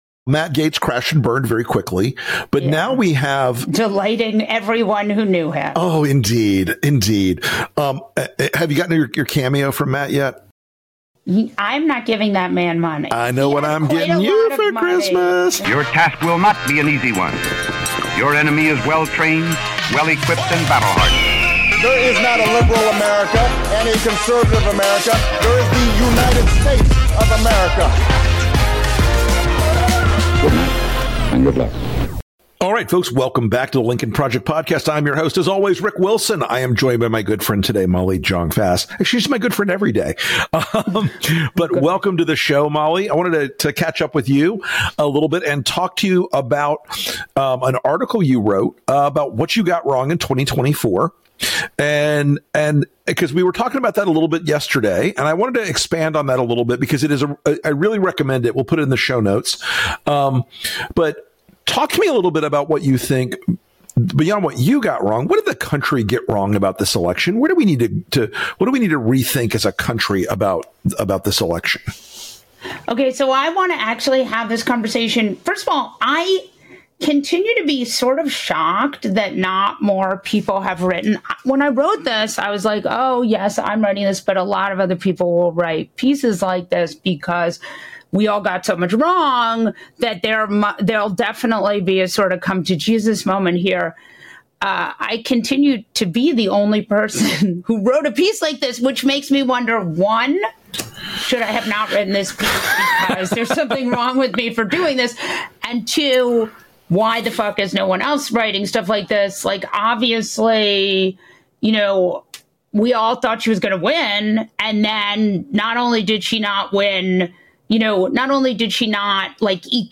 Molly Jong-Fast joins host Rick Wilson to discuss just what the hell went wrong in 2024.